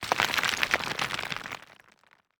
UI_StoneRoll_02.ogg